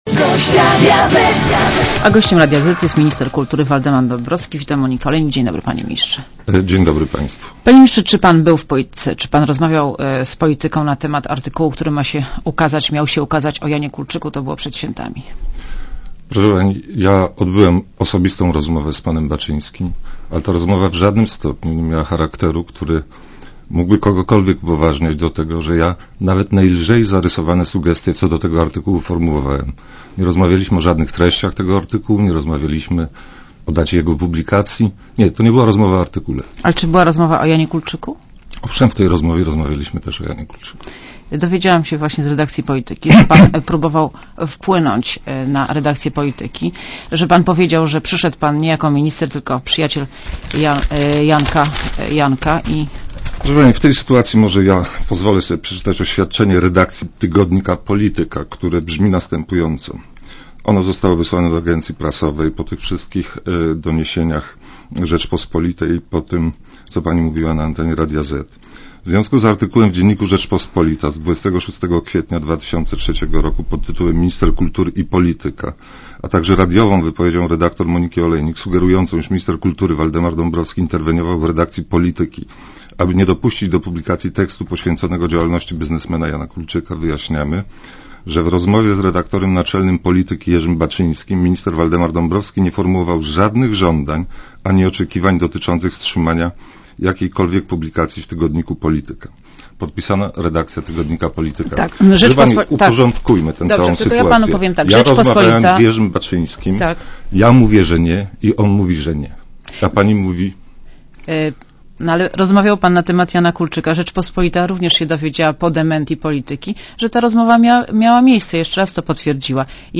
(RadioZet) Źródło: (RadioZet) Posłuchaj wywiadu (2,8 MB) Panie Ministrze, czy pan był w „Polityce”, czy pan rozmawiał z „Polityką” na temat artykułu, który miał się ukazać o Janie Kulczyku?